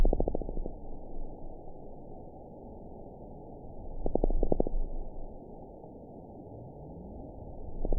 event 920312 date 03/14/24 time 22:41:54 GMT (1 year, 1 month ago) score 7.58 location TSS-AB05 detected by nrw target species NRW annotations +NRW Spectrogram: Frequency (kHz) vs. Time (s) audio not available .wav